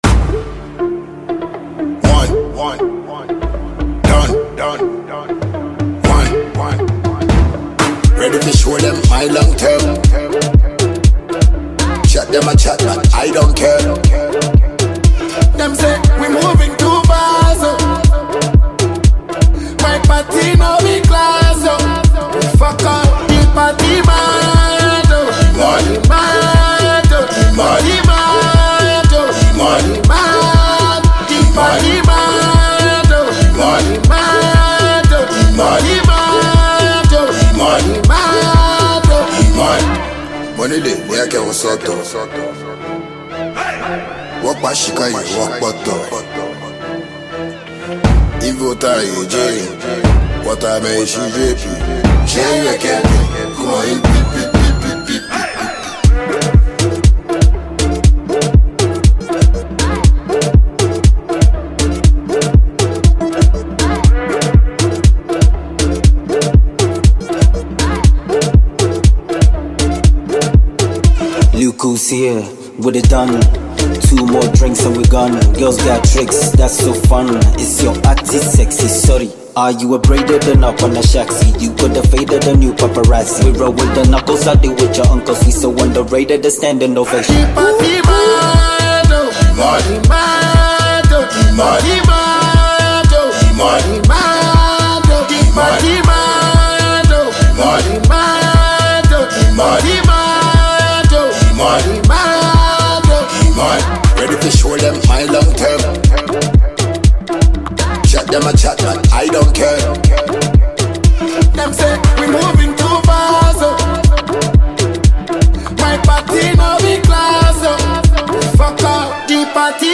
the King of African Dancehall
Enjoy this danceable song and share it.